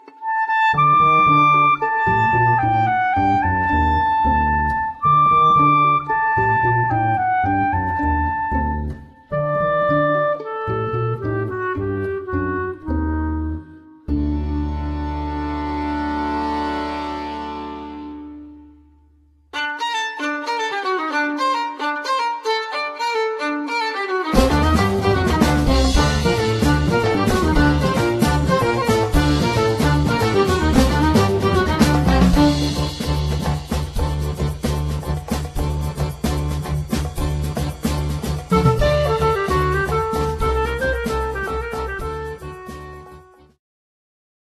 skrzypce / violin
klarnet / clarinet
akordeon / accordion
bębny, perkusja / drums, percussion
kontrabas / double bass